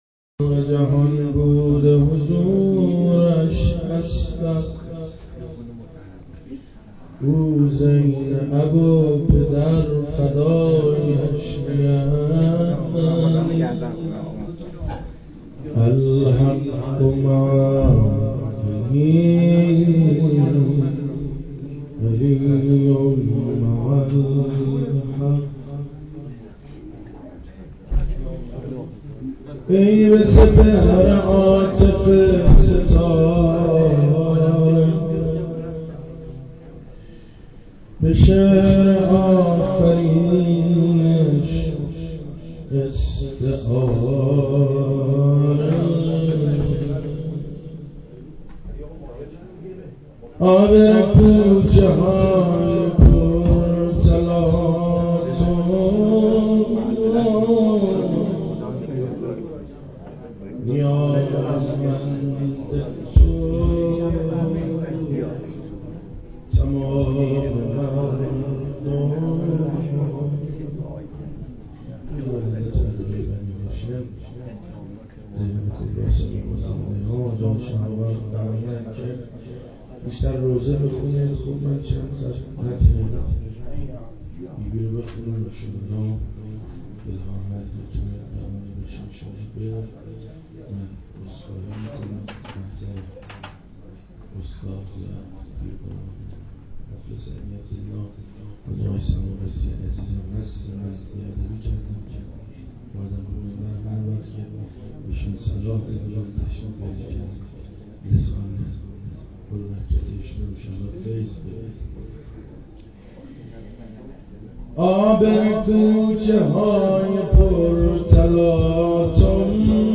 مراسم جشن سالروز میلاد حضرت زینب(س)